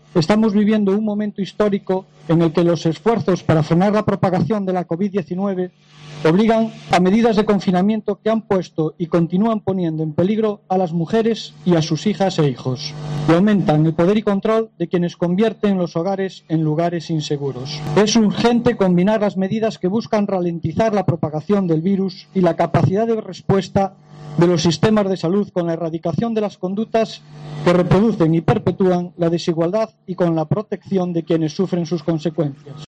El concejal Telmo Ucha leyó el manifiesto de la FEMP